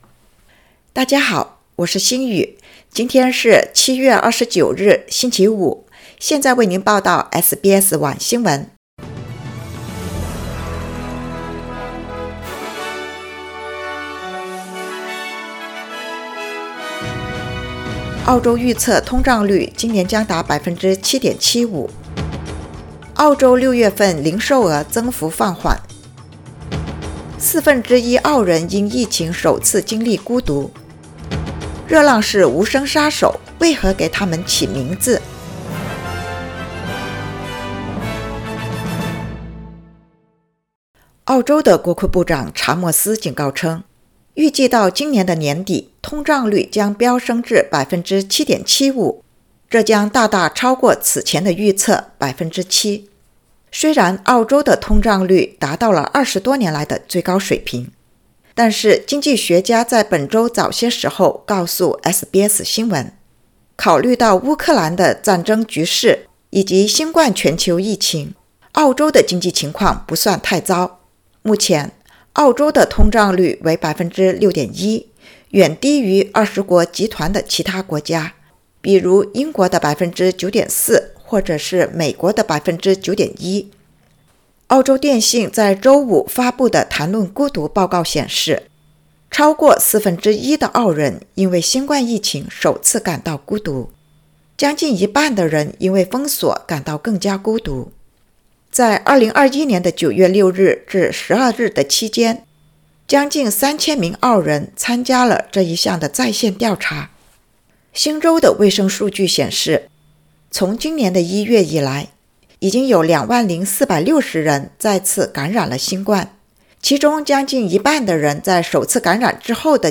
SBS晚新聞（2022年7月29日）
SBS Mandarin evening news Source: Getty Images